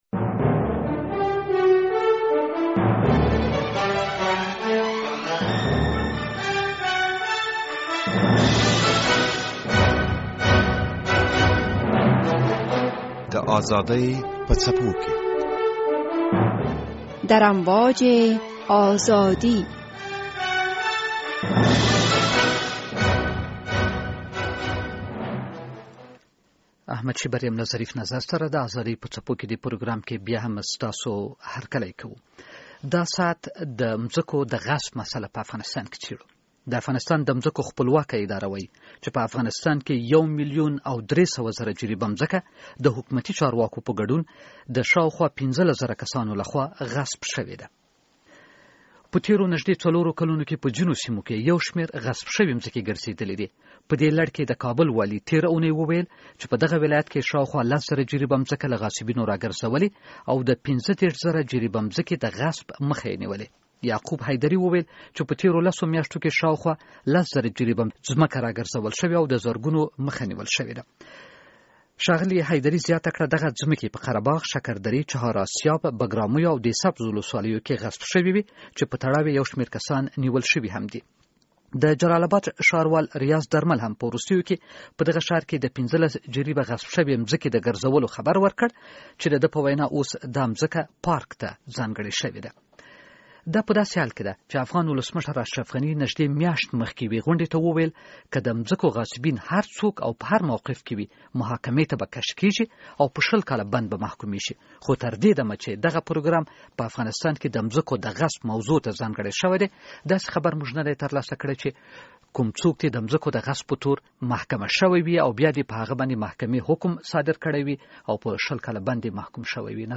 د بحث دوهمه برخه